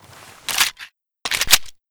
usp_reload.ogg